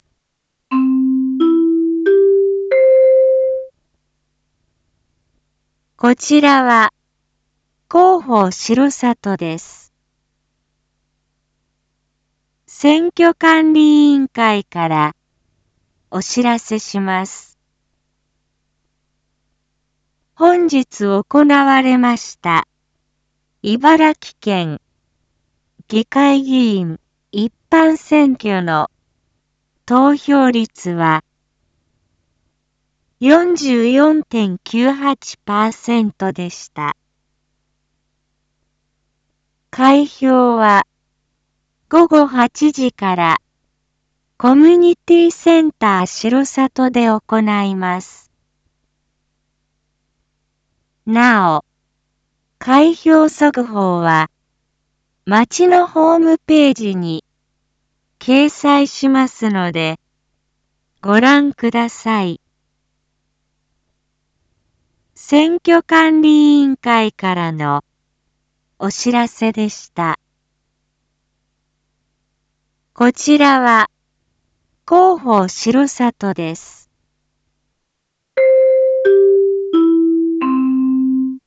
Back Home 一般放送情報 音声放送 再生 一般放送情報 登録日時：2022-12-11 19:16:27 タイトル：県議会議員一般選挙（投票状況）結果 インフォメーション：こちらは、広報しろさとです。